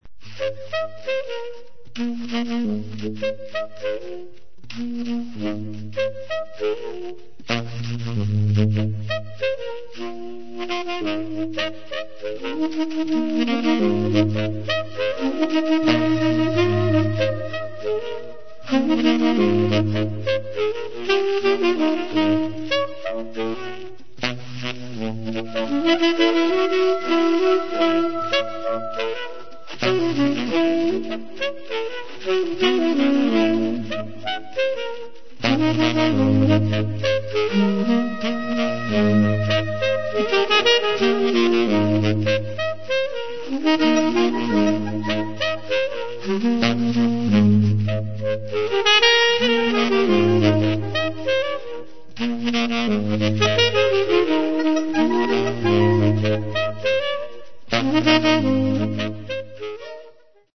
pianoforte
sassofoni
pentagramma ricco di suggestioni world